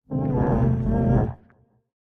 Minecraft Version Minecraft Version latest Latest Release | Latest Snapshot latest / assets / minecraft / sounds / mob / warden / ambient_4.ogg Compare With Compare With Latest Release | Latest Snapshot